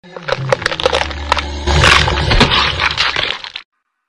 Атакует